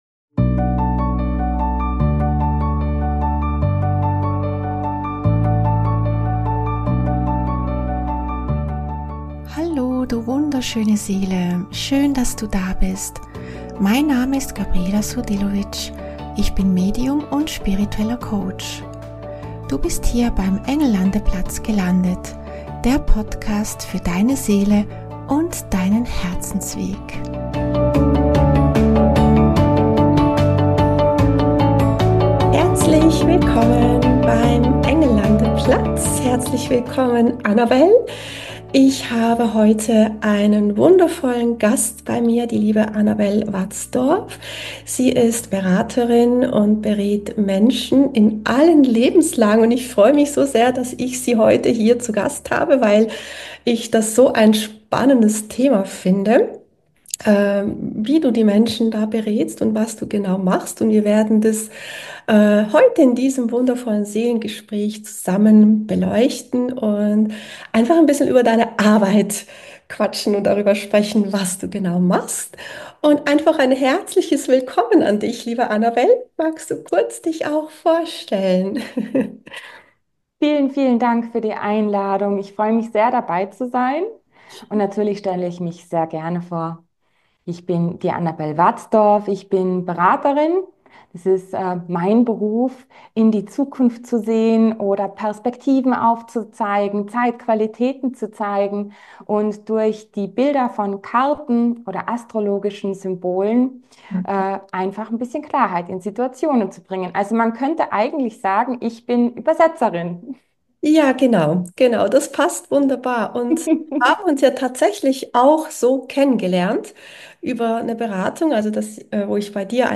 In dieser Folge habe ich wieder einen wundervollen Gast zu Besuch beim Engel Landeplatz.